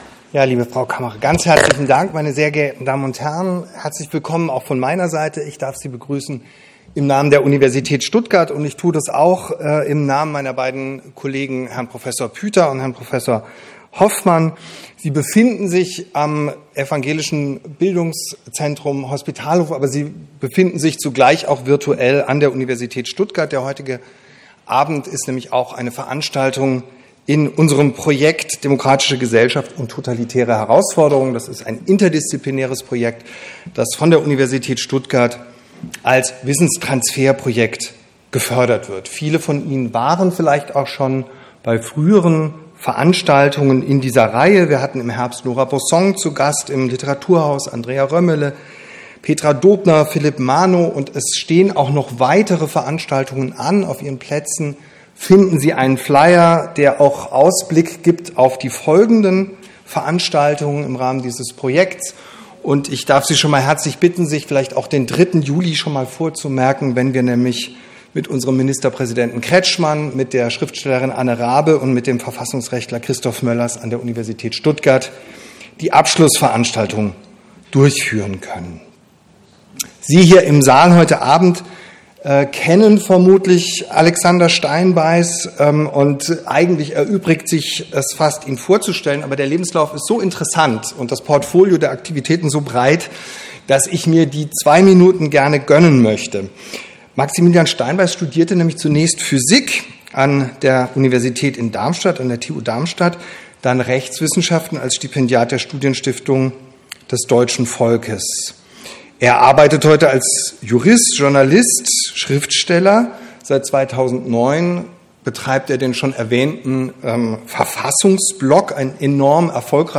Veranstaltungsort: Hospitalhof Stuttgart